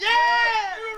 TM88 YeahVox.wav